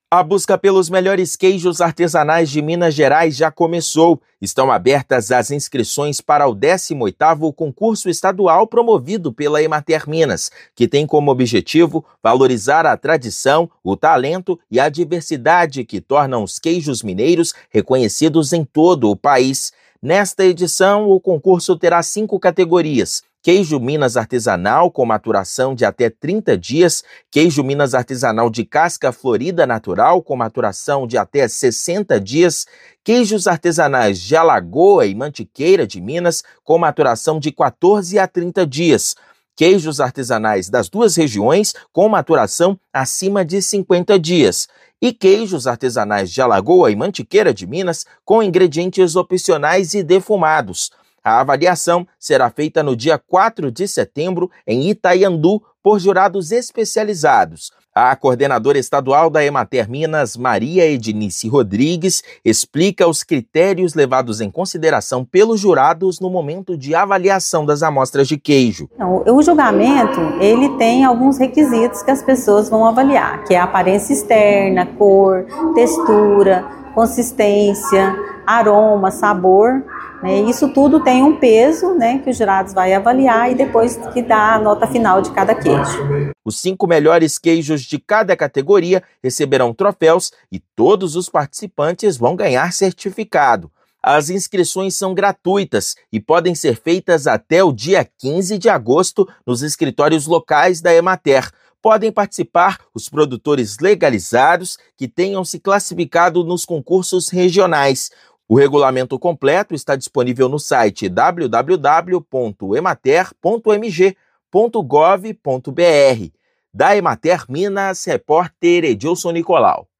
Concurso promovido pela Emater-MG recebe inscrições até 15/8. Ouça matéria de rádio.